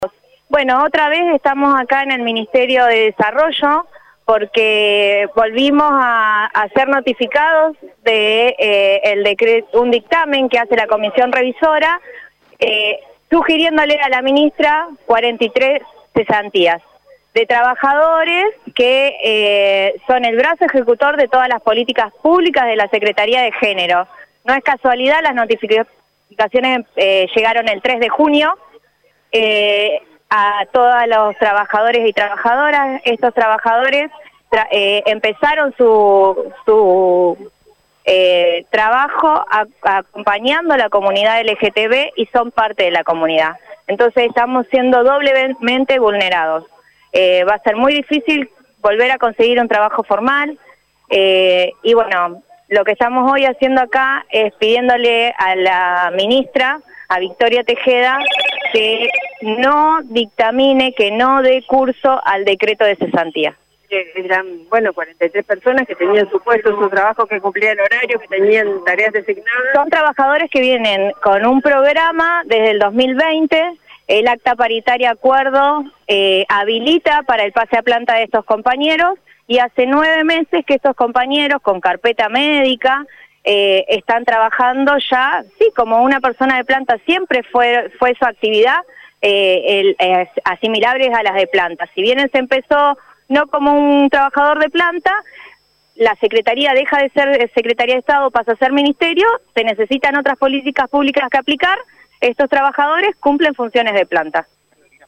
Según explicó una de las delegadas a Radio EME, los 43 casos corresponden a trabajadores que se iniciaron en sus tareas en el año 2020.